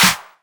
• Clap Sample F Key 08.wav
Royality free clap sound - kick tuned to the F note. Loudest frequency: 4141Hz
clap-sample-f-key-08-rtb.wav